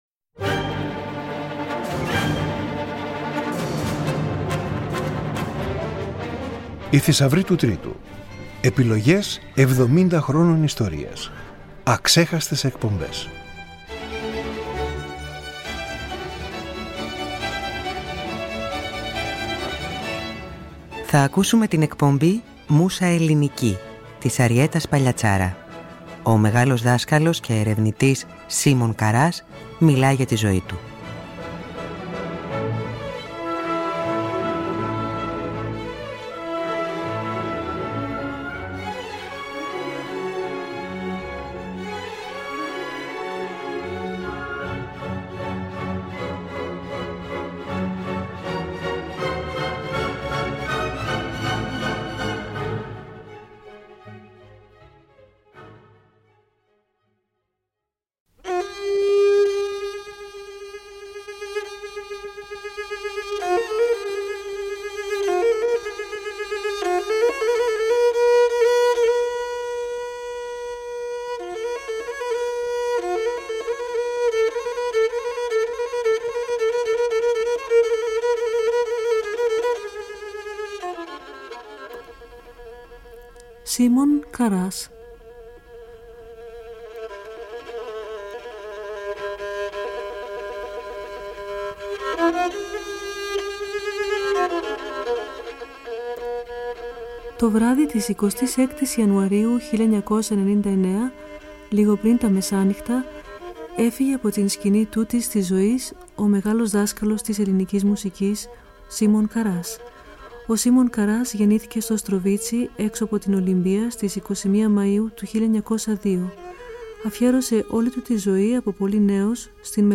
Το Τρίτο Πρόγραμμα στο πλαίσιο του εορτασμού των 70 χρόνων λειτουργίας του ανοίγει τη σειρά μεταδόσεων ΟΙ ΘΗΣΑΥΡΟΙ ΤΟΥ ΤΡΙΤΟΥ, δίνοντας μία καλή αφορμή για να θυμηθούν οι παλαιότεροι και να ακούσουν για πρώτη φορά οι νεότεροι μερικά από τα διαμάντια του αρχειακού υλικού.
Ακούστε στην εκπομπή της Τετάρτης 26 Ιουνίου τον Σίμωνα Καρά, θεμελιωτή της στενής σχέσης του ραδιοφώνου με τη λαϊκή μας μουσική παράδοση σε ένα ακόμη ντοκουμέντο της ενότητας «Αξέχαστες εκπομπές».